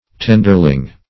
Search Result for " tenderling" : The Collaborative International Dictionary of English v.0.48: Tenderling \Ten"der*ling\, n. 1.
tenderling.mp3